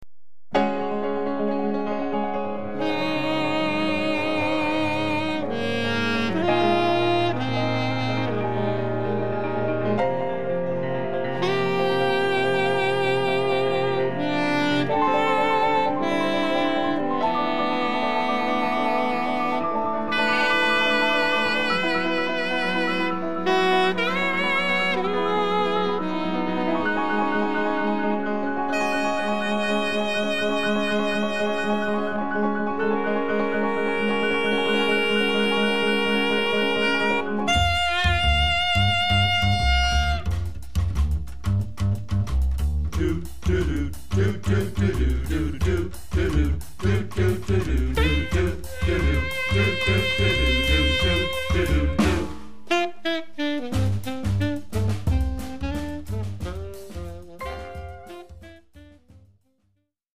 soprano saxophone
alto saxophone
tenor saxophone
baritone saxophone
piano
bass
drums